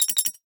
NOTIFICATION_Metal_01_mono.wav